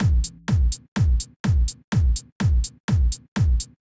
Drumloop 125bpm 06-C.wav